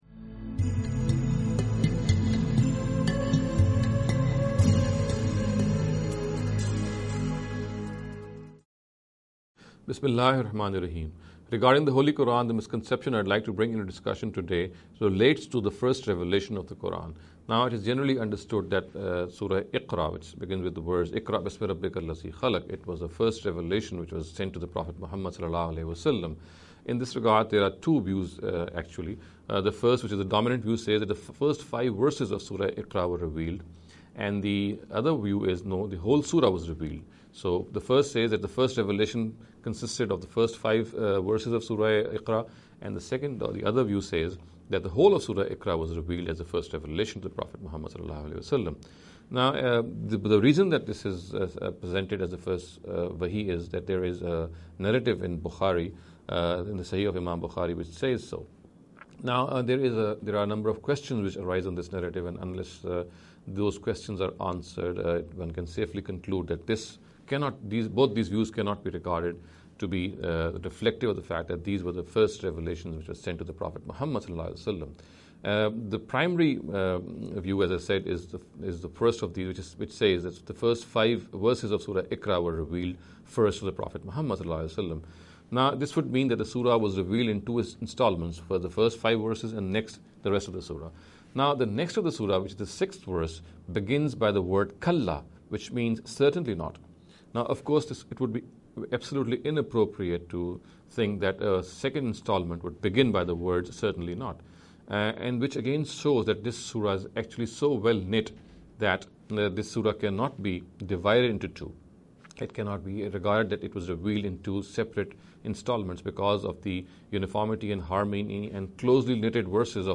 This lecture series will deal with some misconception regarding the Holy Qur’an.